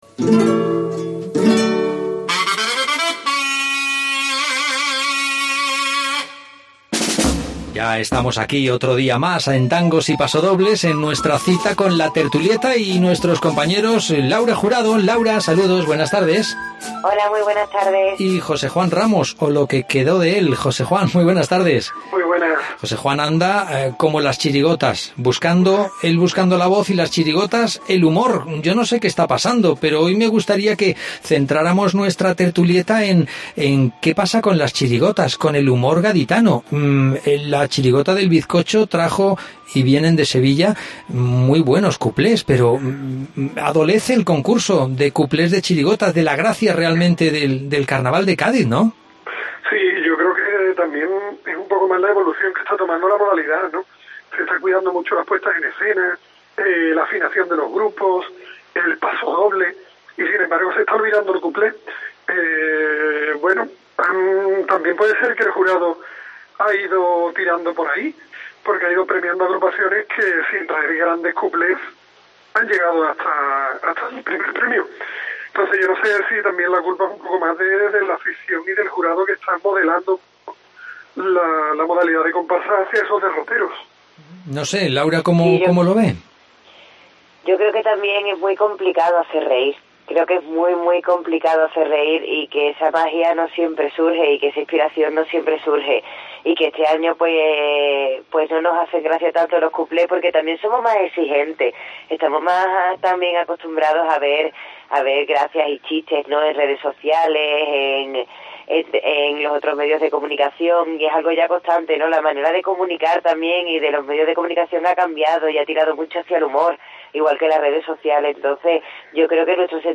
Tangos y Pasodobles
Redacción digital Madrid - Publicado el 07 feb 2018, 12:43 - Actualizado 15 mar 2023, 05:36 1 min lectura Descargar Facebook Twitter Whatsapp Telegram Enviar por email Copiar enlace Seguimos de cerca, todo lo que ocurre en el COAC 2018 desde el Gran Teatro Falla.